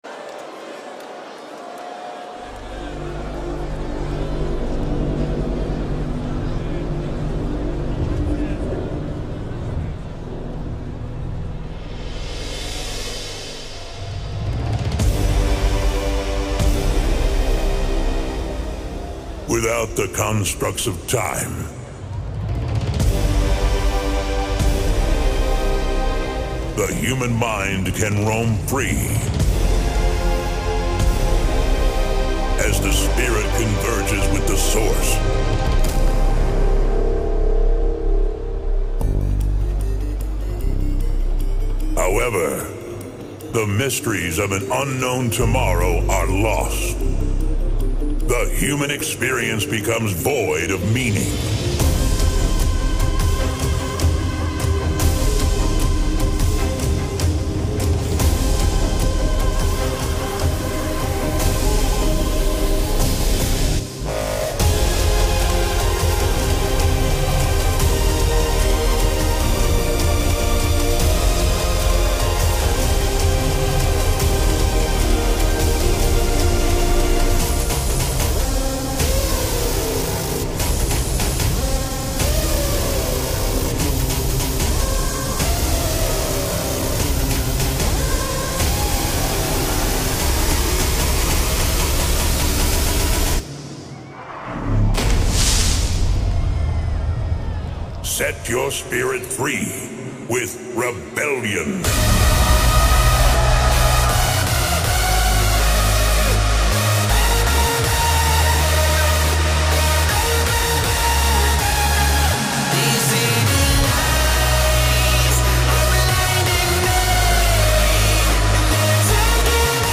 DJ Mixes and Radio Show